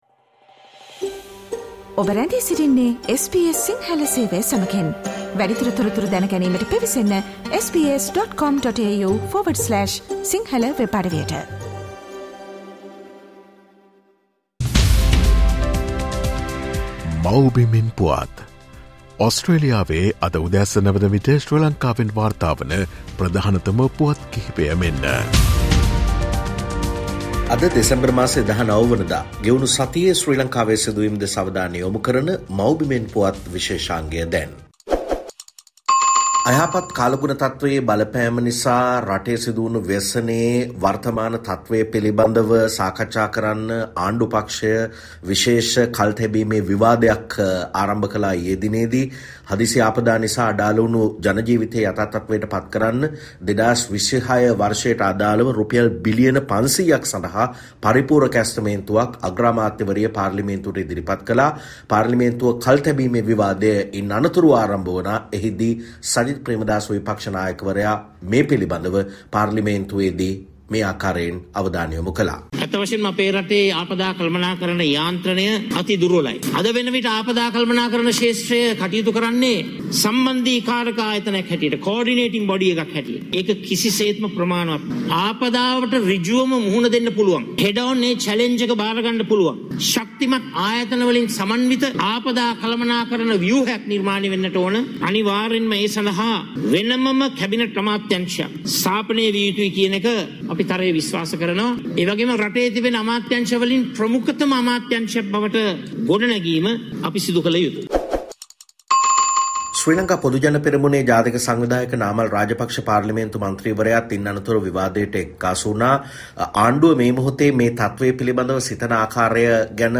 මේ සතියේ ශ්‍රී ලංකාවෙන් වාර්තා වූ උණුසුම් හා වැදගත් පුවත් සම්පිණ්ඩනය.